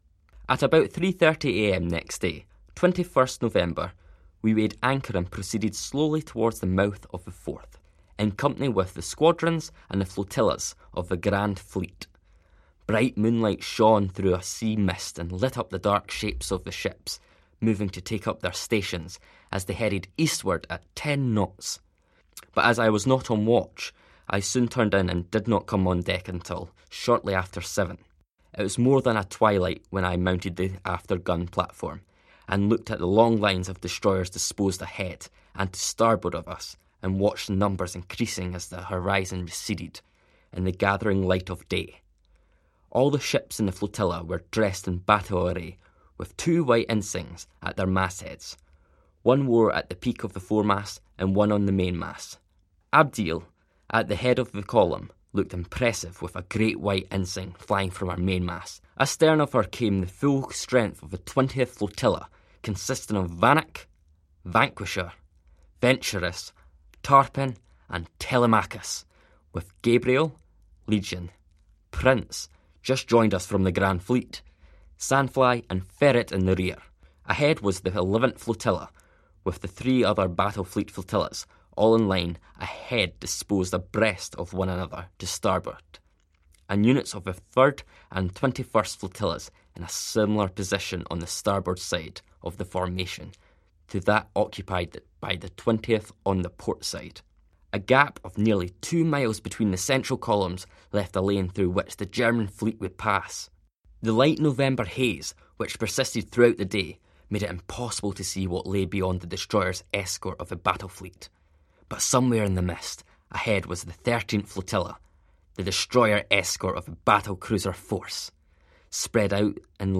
Here is a recording of the final chapter of his diary, covering the Surrender of the German High Seas Fleet in the Forth, November 2018.